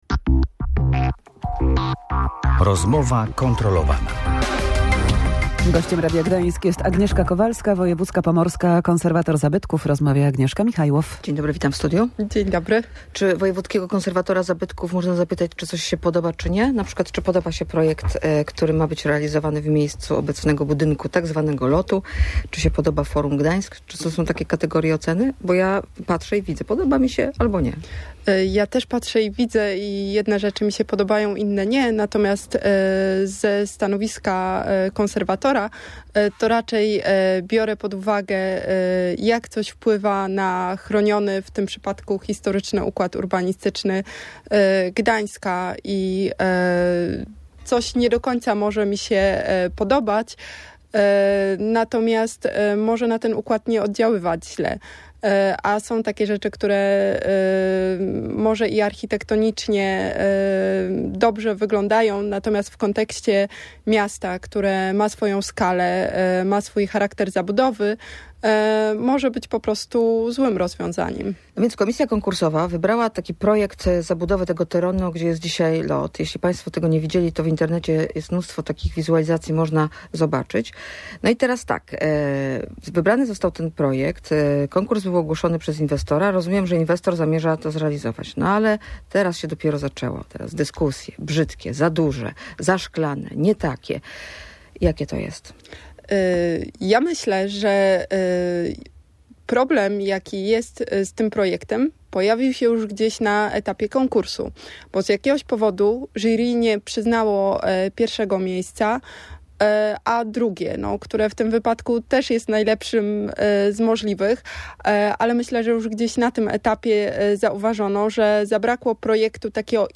– Tak jak inni patrzę i widzę. Jedne rzeczy podobają mi się bardziej, inne mniej – mówiła na antenie Radia Gdańsk pomorska wojewódzka konserwator zabytków.